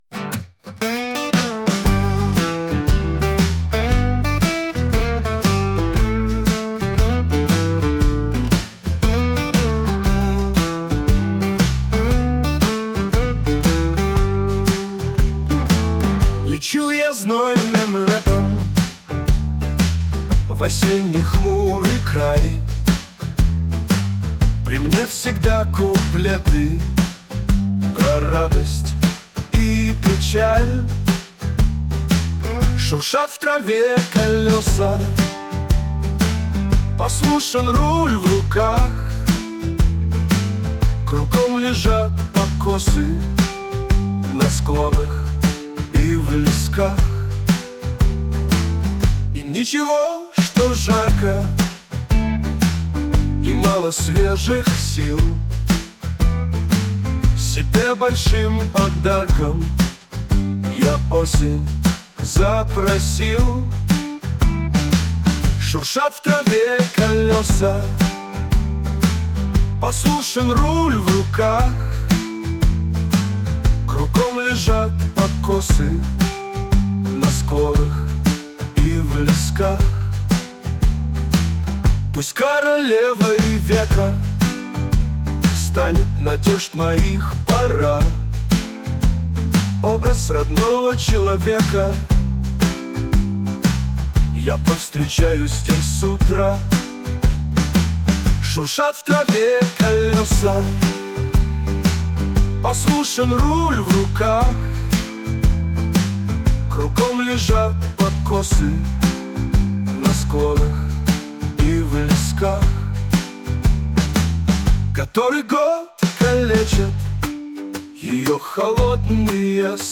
Мои стихи + музыка ИИ
ТИП: Пісня
СТИЛЬОВІ ЖАНРИ: Ліричний